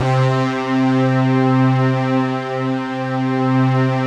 Index of /90_sSampleCDs/Optical Media International - Sonic Images Library/SI1_ObieStack/SI1_OBrass Stabs